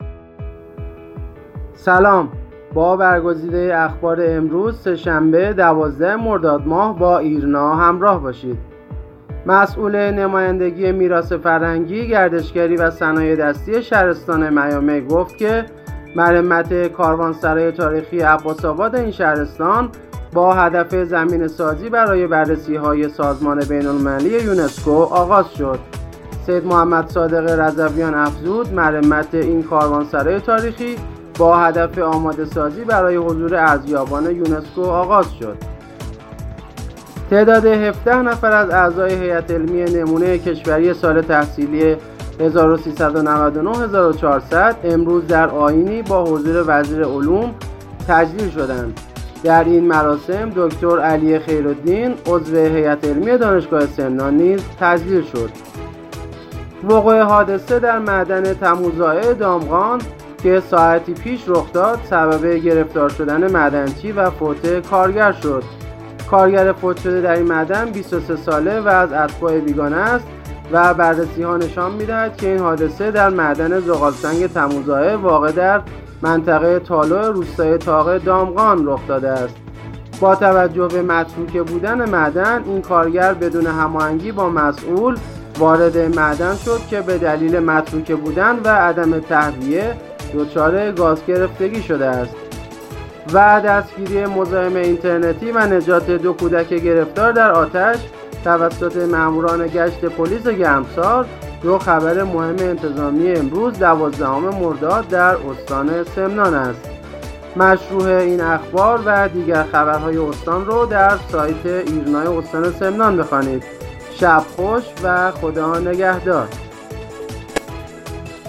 پادکست اخبار سه‌شنبه ۱۲ مرداد استان سمنان